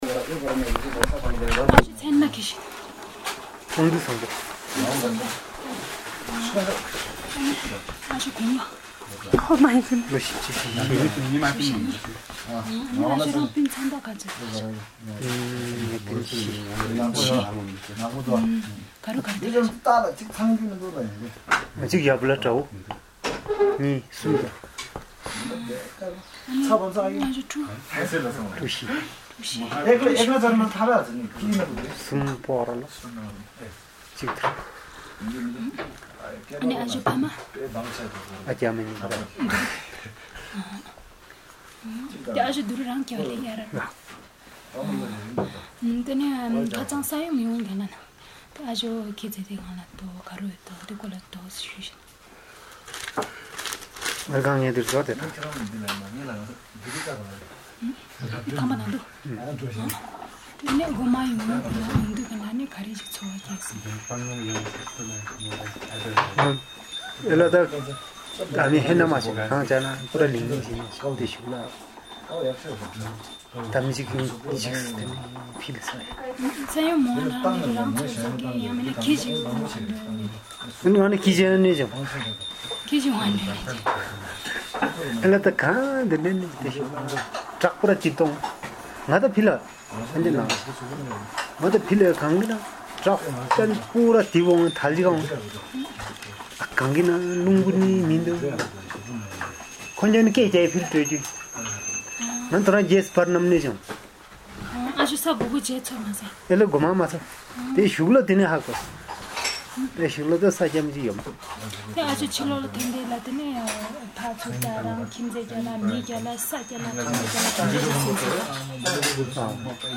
Interview of a community member on the 2015 Nepal Earthquakes
Audio Interview